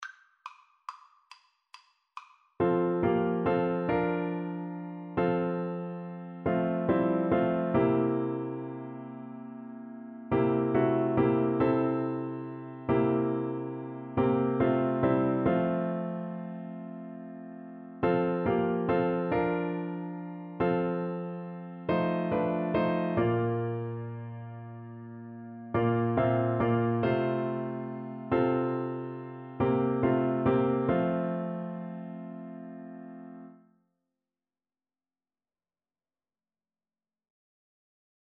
9/4 (View more 9/4 Music)